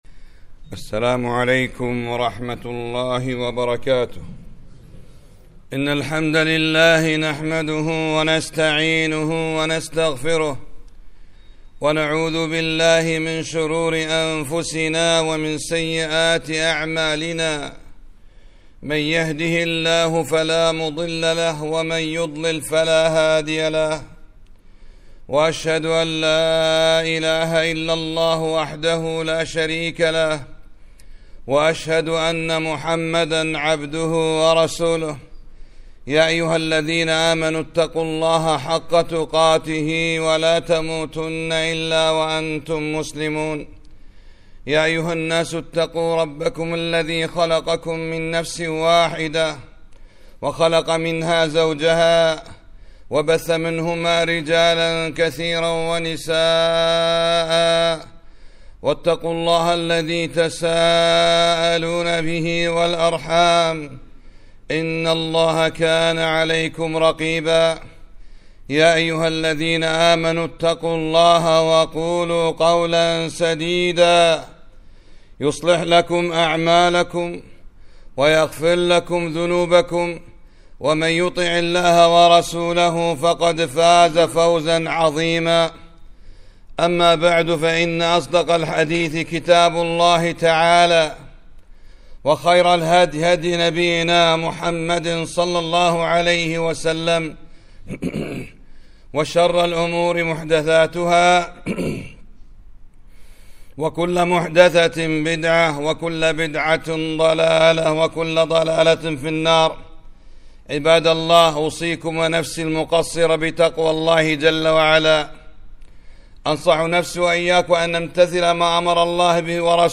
خطبة - لا حول ولا قوة إلا بالله - كنز من كنوز الجنة -